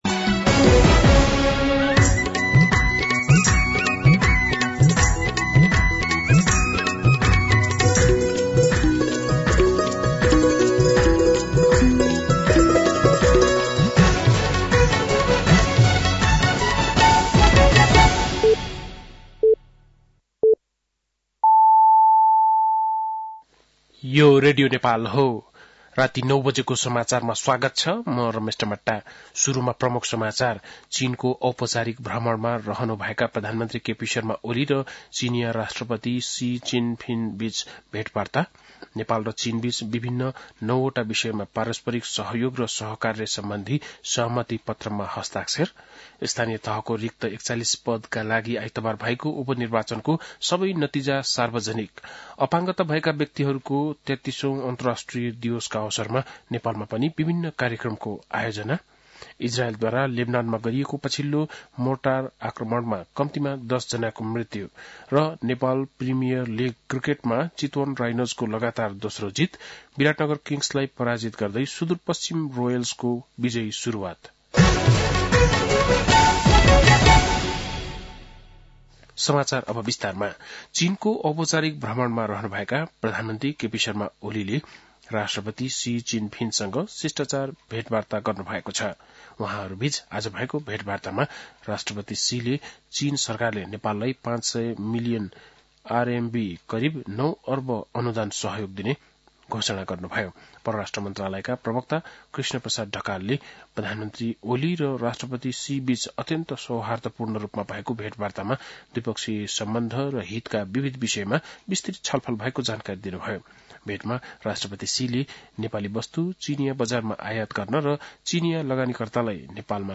बेलुकी ९ बजेको नेपाली समाचार : १९ मंसिर , २०८१
9-PM-Nepali-News-8-18.mp3